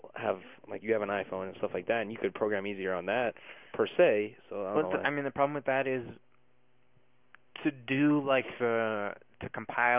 Functions of Bookended Narrow-Pitch-Range Regions